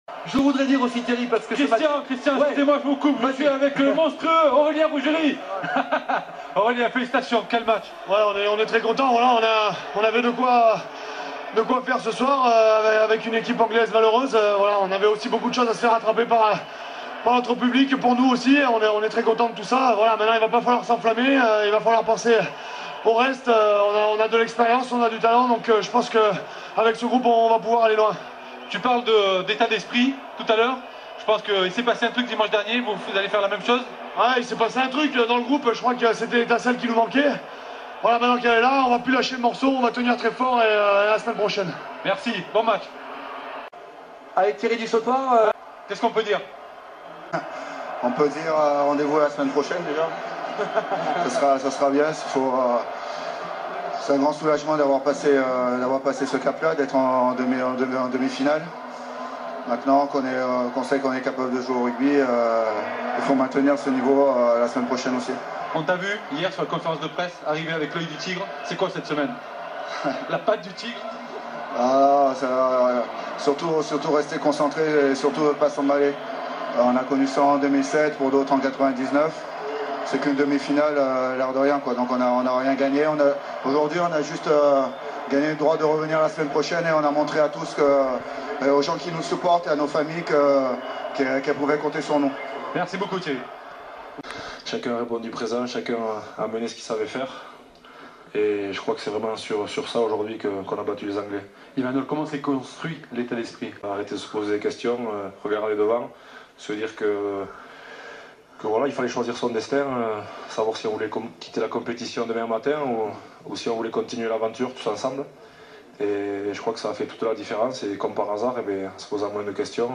D’abord les réactions à chaud de quelques joueurs et de l’entraîneur juste après la victoire, entre euphorie, esprit d’équipe et humilité – ce qui n’est pas la vertu la mieux partagée dans d’autres sports… Suivez mon regard !